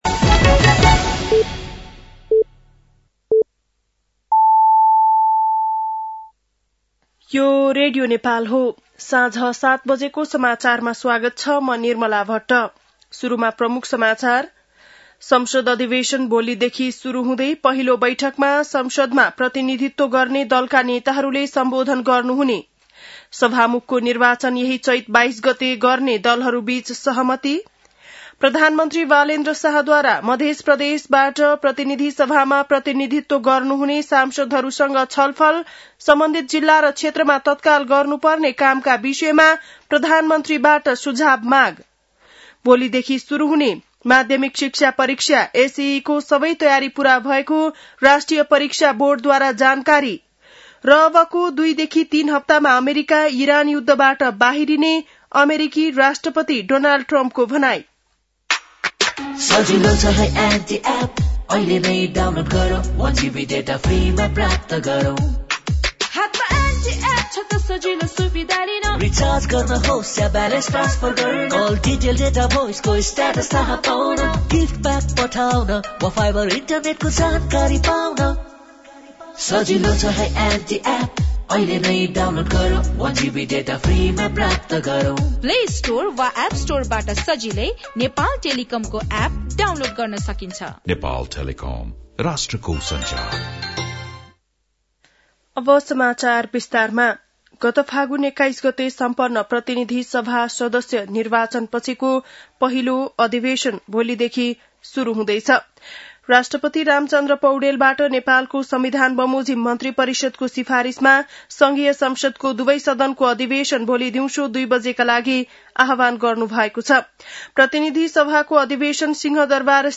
बेलुकी ७ बजेको नेपाली समाचार : १८ चैत , २०८२